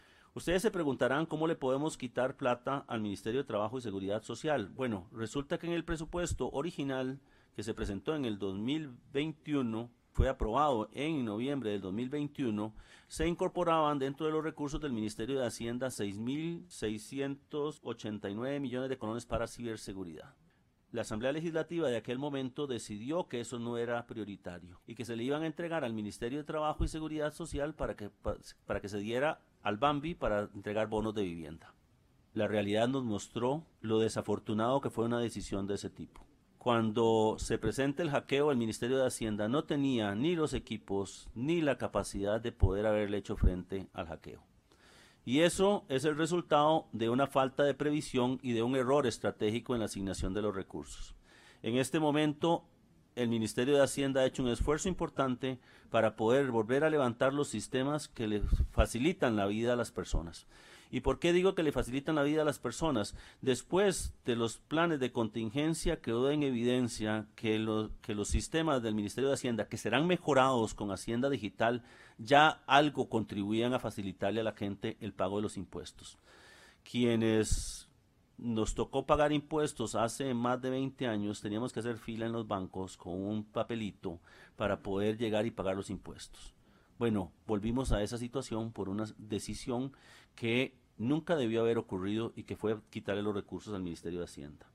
Nogui Acosta ante los diputados que integran la Comisión de Hacendarios